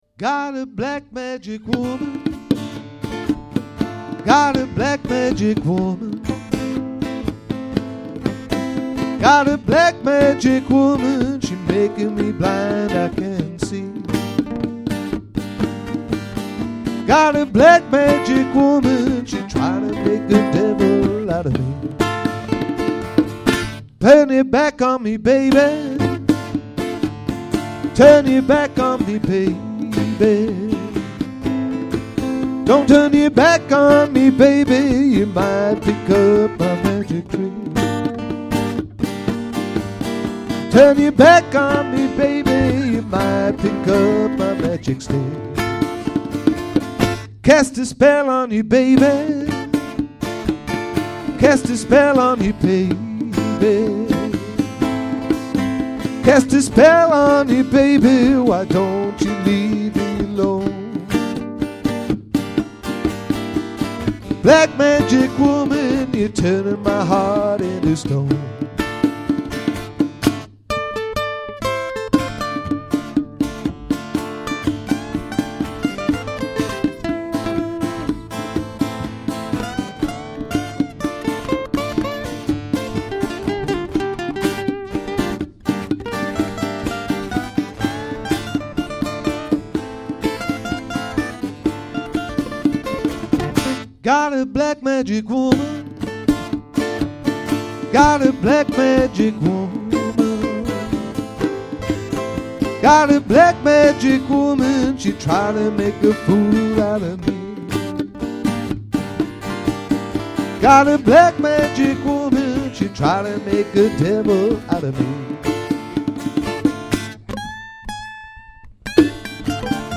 Ashington Folk Club - Spotlight 16 November 2006
guitar and vocals
bongos and vocals
Full song performed by The Three Amigos